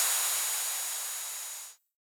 RDM_Raw_SY1-OpHat.wav